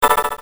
cartoon14.mp3